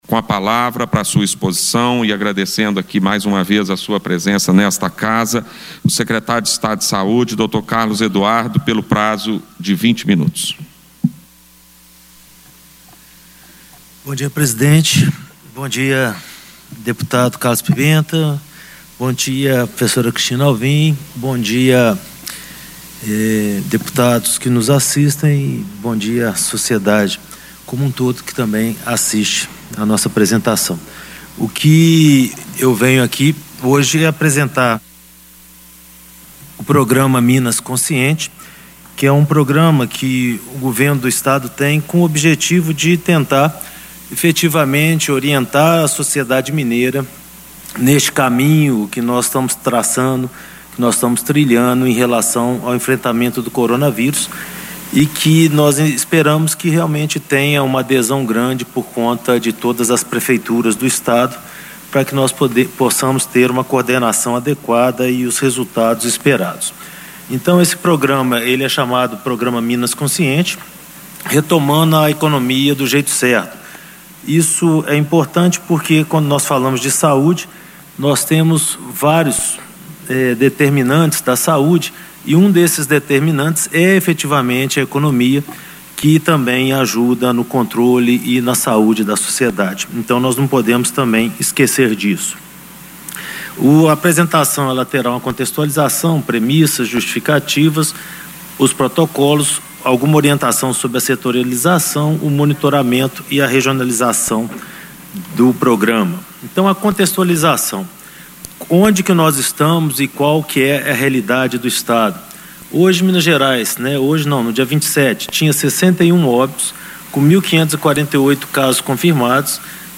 Na íntegra do pronunciamento em audiência no Plenário, o secretário destaca visões importantes a respeito da contaminação pela Covid-19 e sua relação com a retomada da atividade econômica, prevista em planos do Governo de Minas.